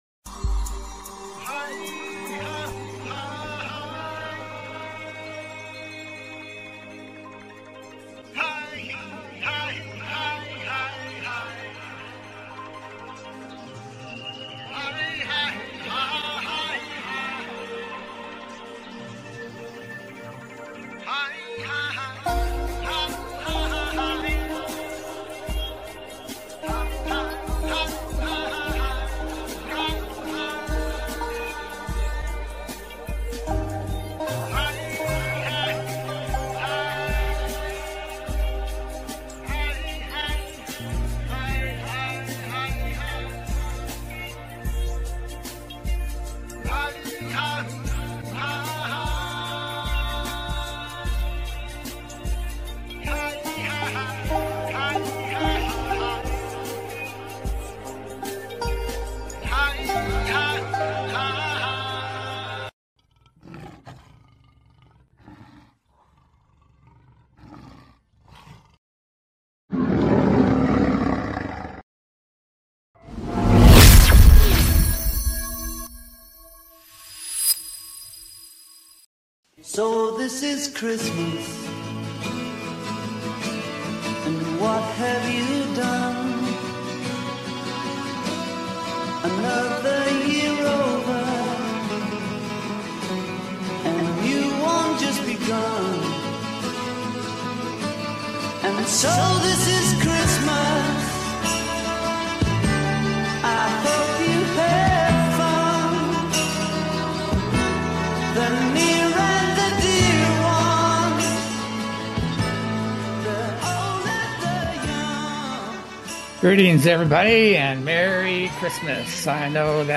Talk Show Episode, Audio Podcast, As You Wish Talk Radio and Christmas Edition, Time To Take The Blinders Off on , show guests , about Christmas Edition,Time To Take The Blinders Off, categorized as Earth & Space,News,Paranormal,UFOs,Philosophy,Politics & Government,Science,Spiritual,Theory & Conspiracy
As you Wish Talk Radio, cutting edge authors, healers & scientists broadcasted Live from the ECETI ranch, an internationally known UFO & Paranormal hot spot.